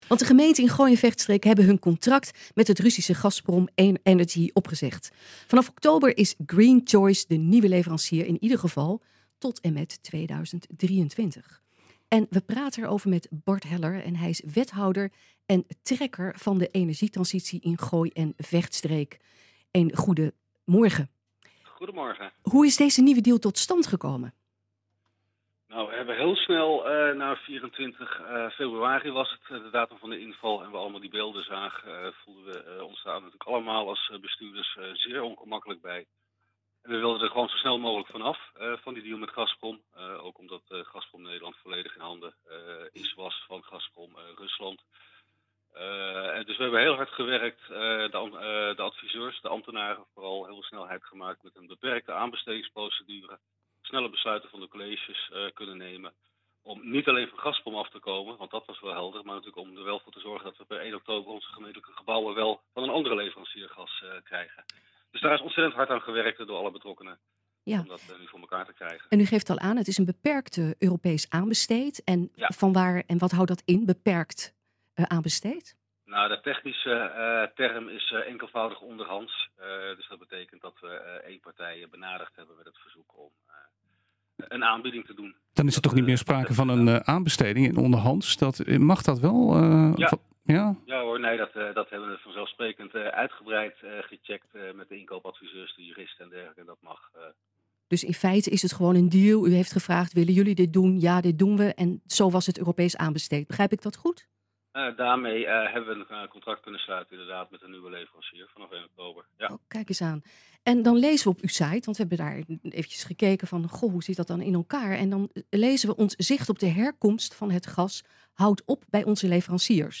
Luister het hele interview met Bart Heller terug via deze link.
We gaan erover praten met Bart Heller, Wethouder en Trekker van de Energietransitie in Gooi en Vechtstreek.
nh-gooi-zaterdag-bart-heller-over-herkomst-vervanger-gemeentelijke-leverancier-gazprom.mp3